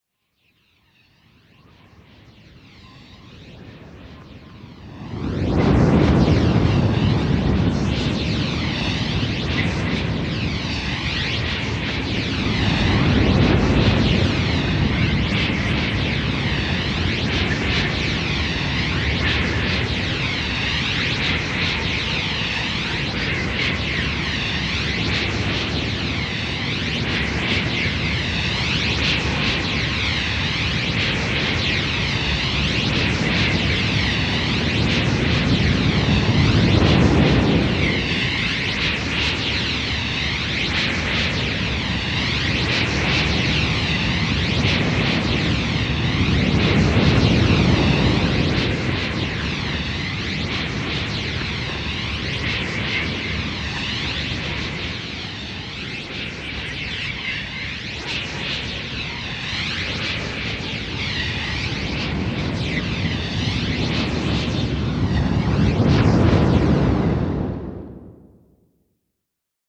На этой странице собраны разнообразные звуки Змея Горыныча: от грозного рыка до зловещего шипения.
Шум крыльев Змея Горыныча